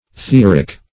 Theoric \The*or"ic\, a. [Cf. F. th['e]orique.
Theoric \The"o*ric\, n. [OF. theorique; cf. L. theorice.]
theoric.mp3